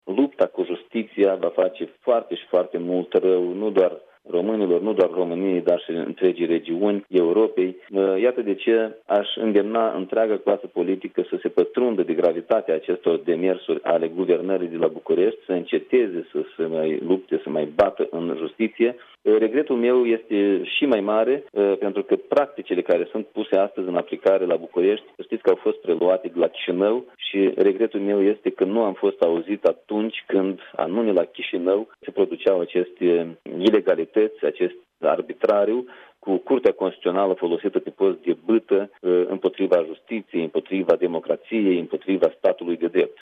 Într-un interviu în exclusivitate pentru Europa FM, liderul Platformei  Demnitate și Adevăr din Republica Moldova spune că prin atacarea Justiției, PSD și ALDE par să se inspire din acțiunile comise de oligarhii care conduc Republica Moldova.